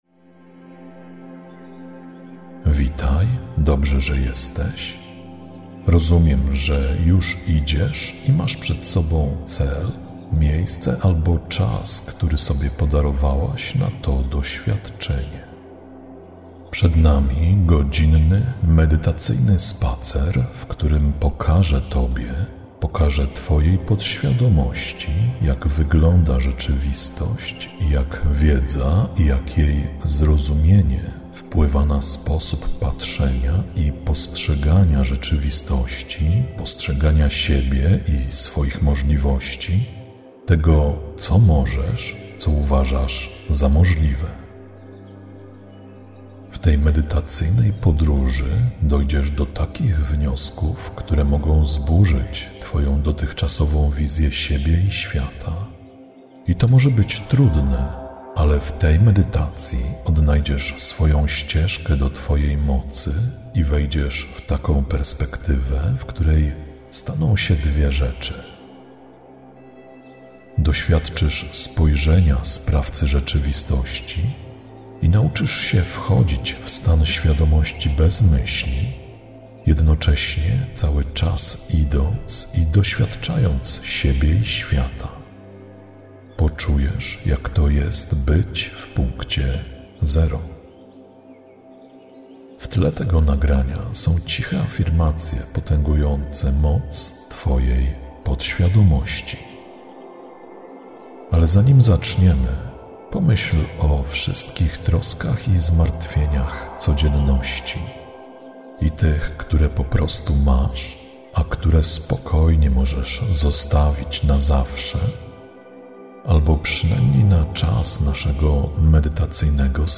Zawiera lektora: Tak